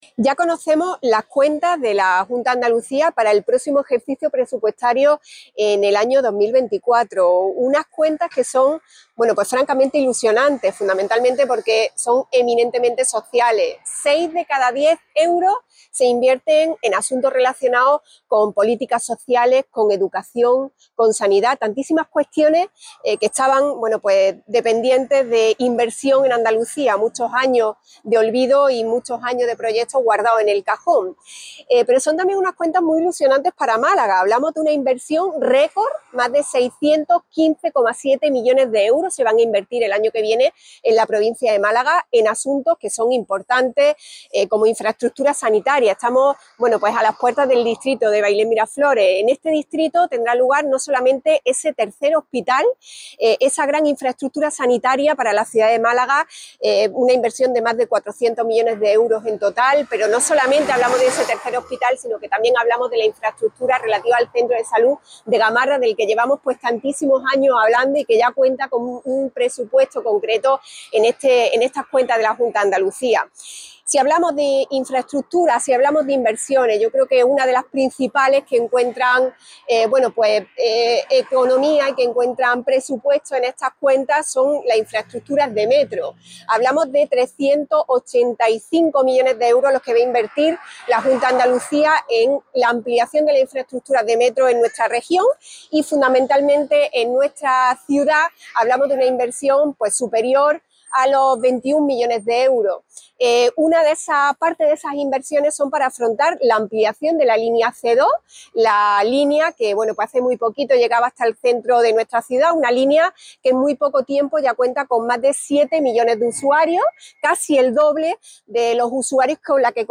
Elisa Pérez de Siles, portavoz del PP de Málaga